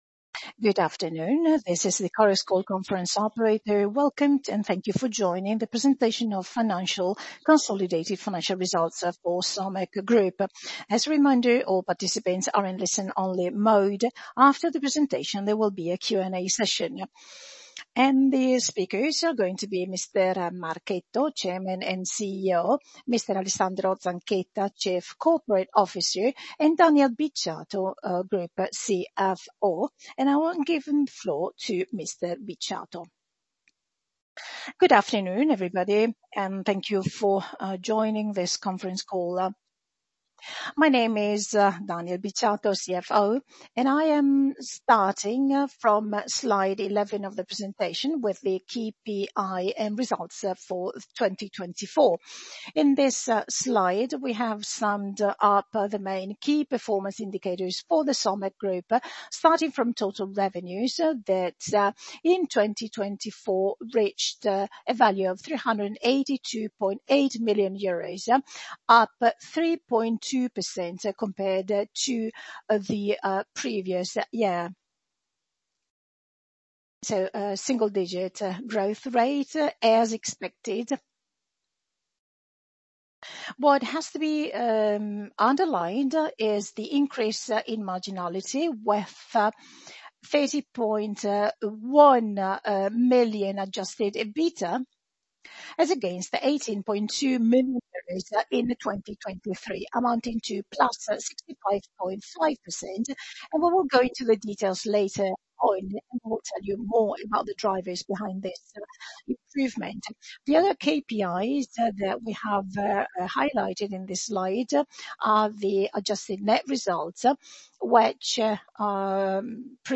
FY 2024 Results presentation conference call (audio)
Somec_Results-Presentation-FY-2024.mp3